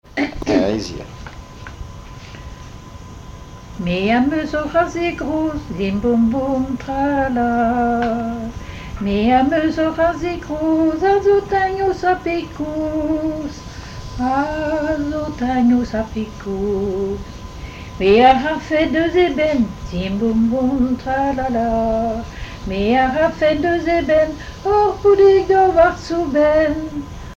Enquête Douarnenez en chansons
Témoignages et chansons
Pièce musicale inédite